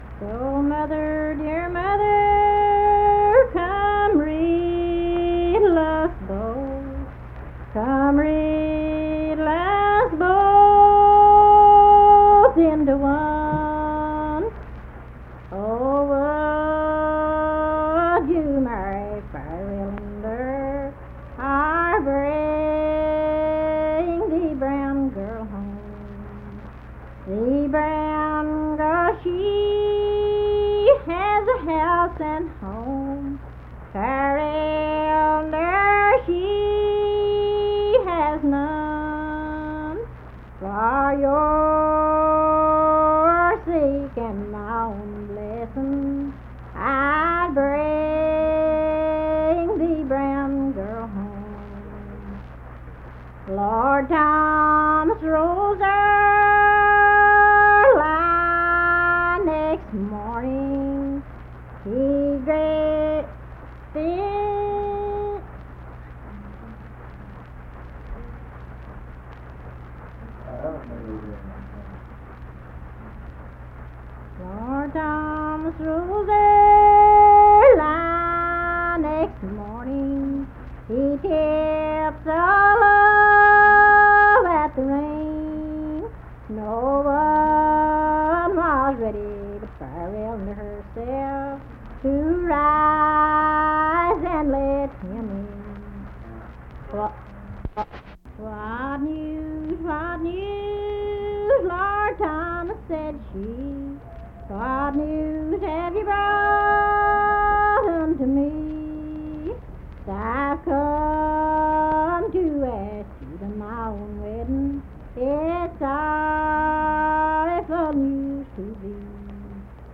Unaccompanied vocal music
Verse-refrain 17(4).
Voice (sung)